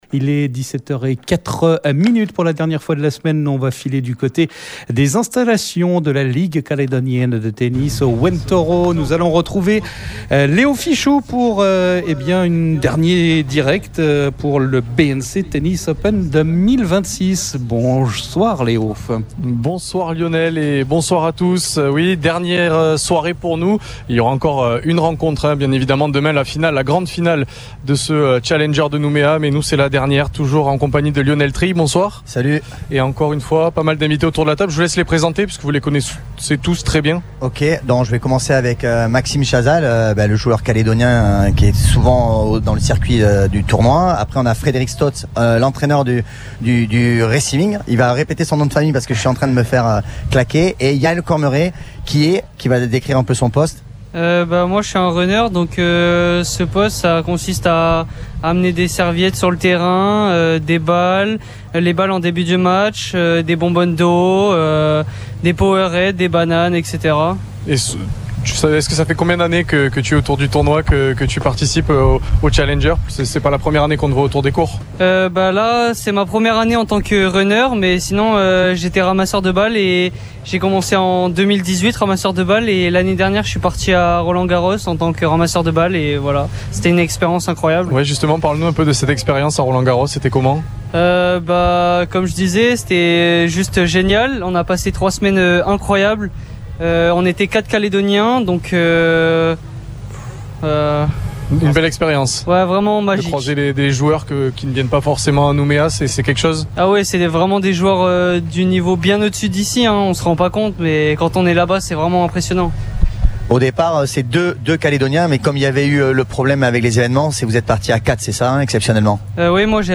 Le BNC Tennis Open 2026 sur les courts du Ouen Toro.